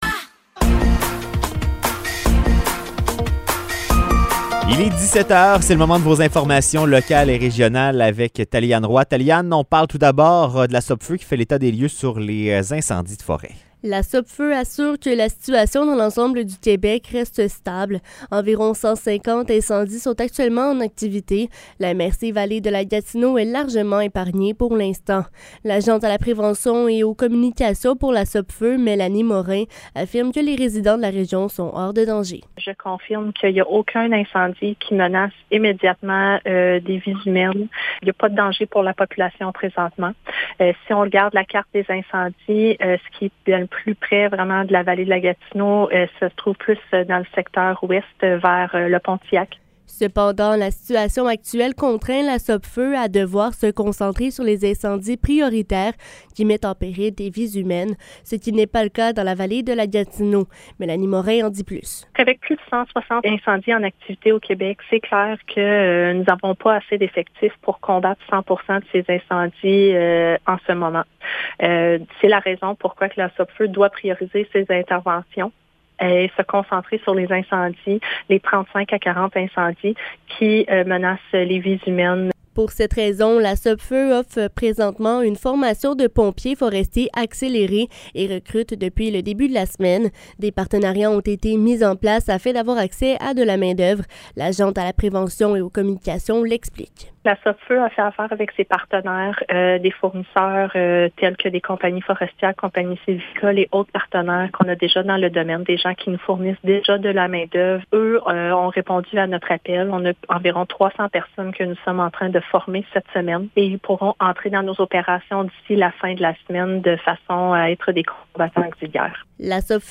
Nouvelles locales - 6 juin 2023 - 17 h